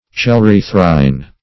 Search Result for " chelerythrine" : The Collaborative International Dictionary of English v.0.48: Chelerythrine \Chel`e*ryth"rine\, n. [Gr.
chelerythrine.mp3